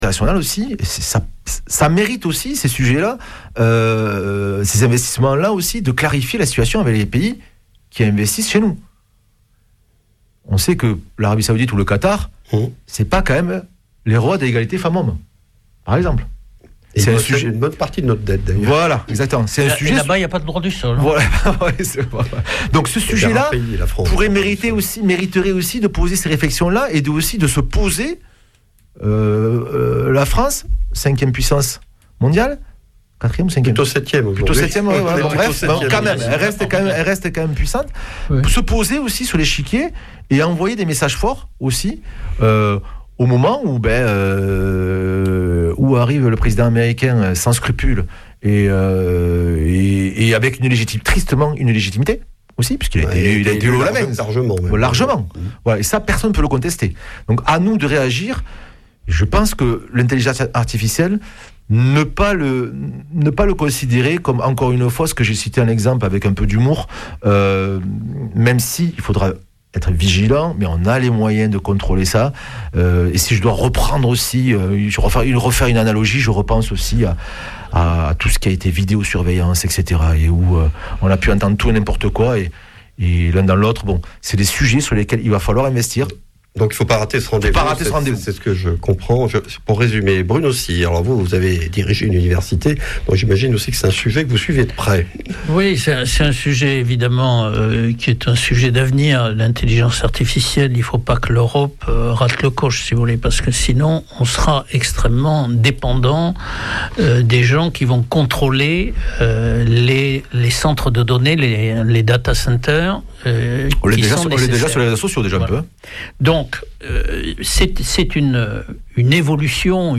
Vêpres de Saint Sernin du 16 févr.